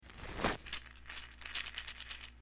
box_eating.mp3